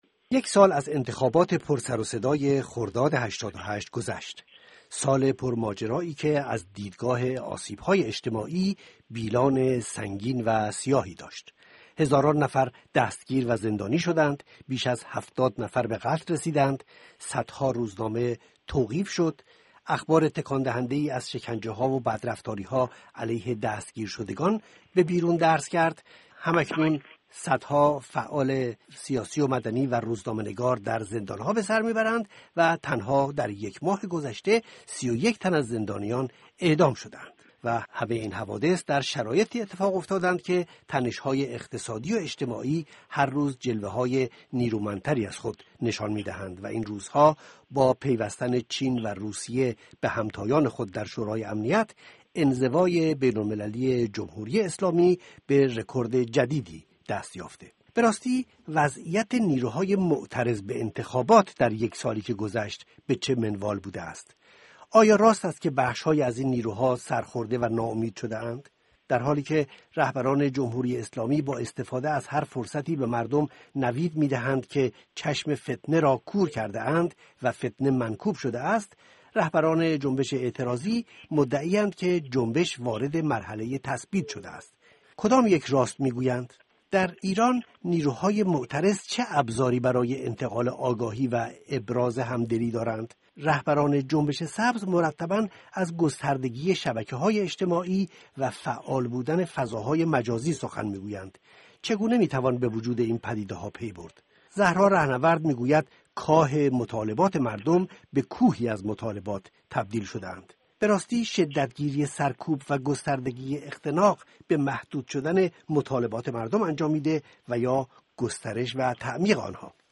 مجله رادیوئی زمینه ها و زمانه ها